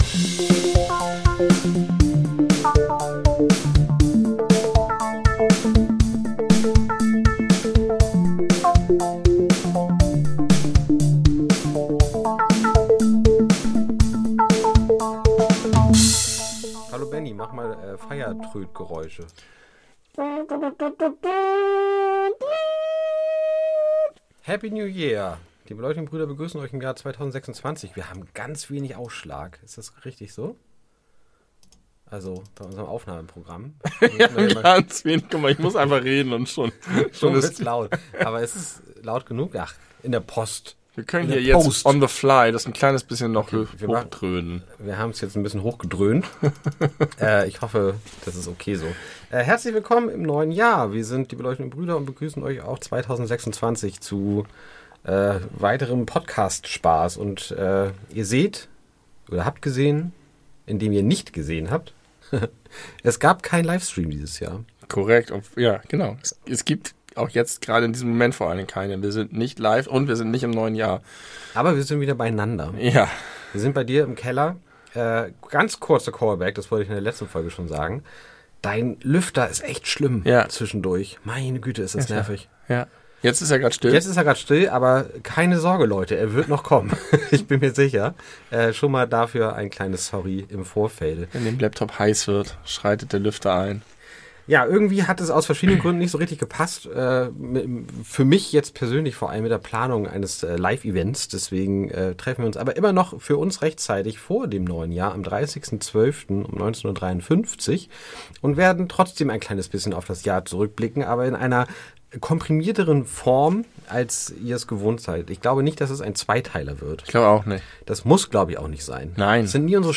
Natürlich muss auch das vergangene Jahr kurz zusammengefasst werden, allerdings dieses Mal nicht live im Internet.